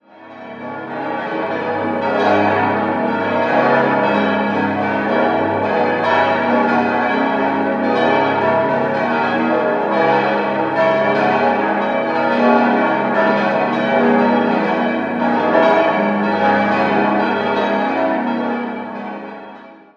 St. Heinrich gehört zu den ersten Sichtbeton-Kirchen in Deutschland und entstand in den Übergangsjahren vom historisierenden hin zum modernen Kirchenbau. 8-stimmiges Geläut: gis°-cis'-dis'-e'-fis'-gis'-ais'-cis'' Alle Glocken wurden 1958 vom Bochumer Verein für Gussstahlfabrikation gegossen und bilden dessen umfangreichstes Gesamtgeläut.